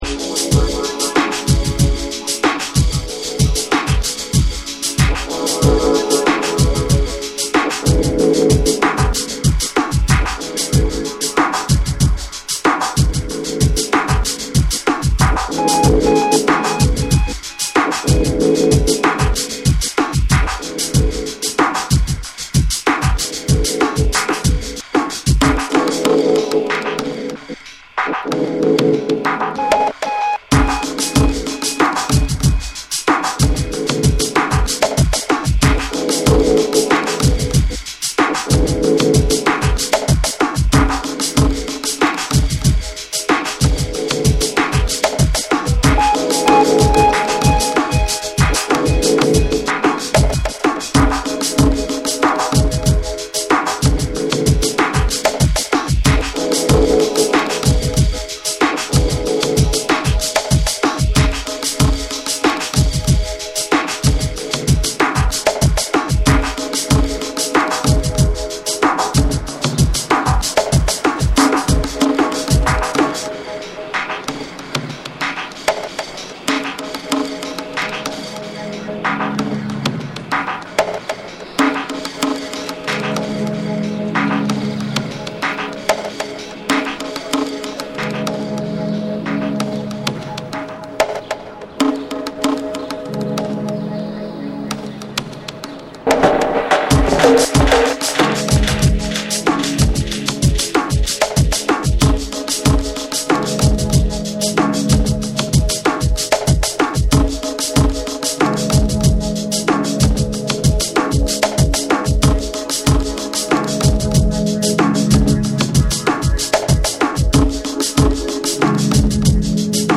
緻密なプログラミングと躍動感あるビートで展開するテクノと浮遊感のある幻想的なアブスト・ブレイクビーツを収録。
JAPANESE / TECHNO & HOUSE / BREAKBEATS